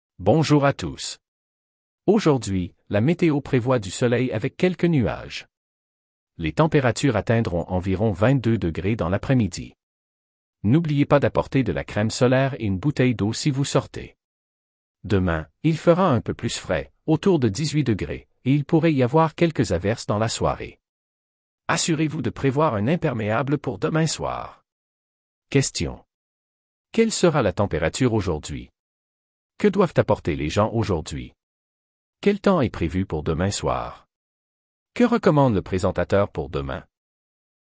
A weather report for a school trip.
Weather Reporter:
Meteo.mp3